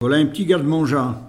Foussais-Payré
Catégorie Locution